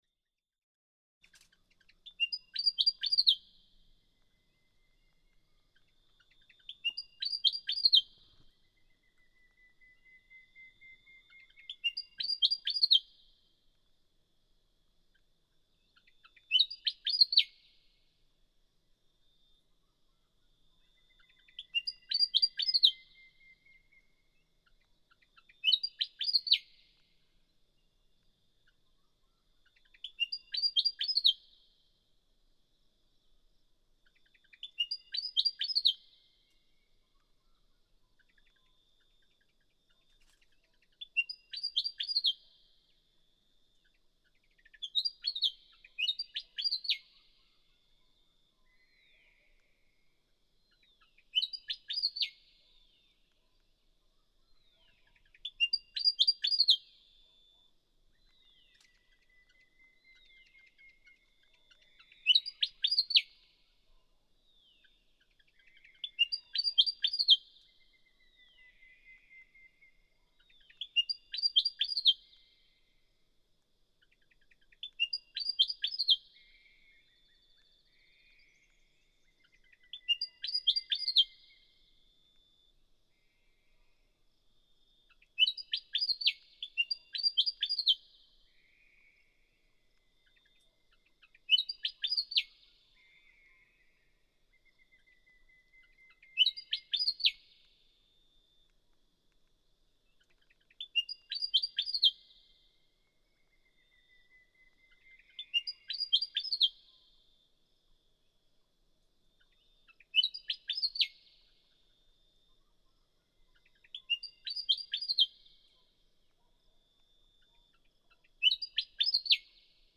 Willie wagtail
Before almost all other birds are stirring, while it's still pitch-black out, the willie sings from a bush beside the road.
Sweet, pretty creature he is said to sing. Listen carefully and you may hear him play with two different songs.
Jandowae, Western Downs Region, Queensland.(13:34)
732_Willie_Wagtail.mp3